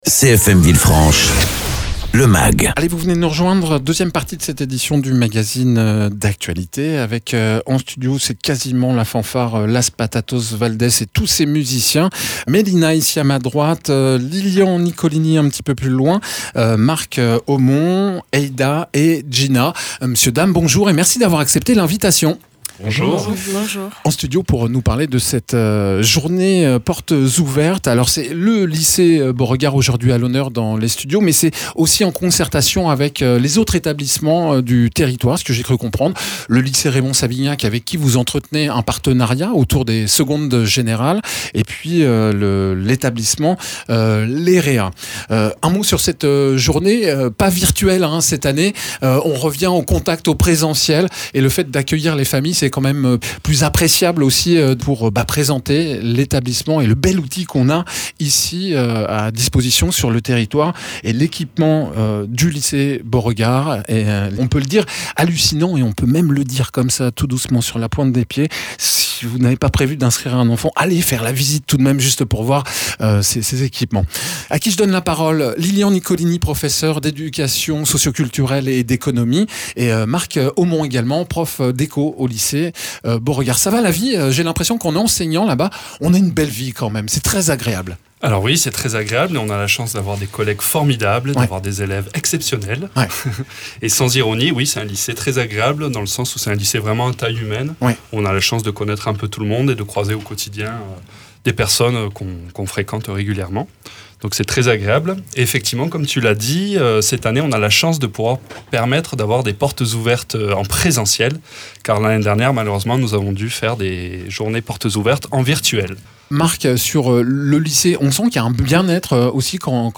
Deux enseignants et trois élèves présentent les atouts de leur lycée, filières, futurs projets, équipements, activités à l’occasion de la journée portes ouvertes programmés ce samedi 12 mars de 9 h à 16h.
Interviews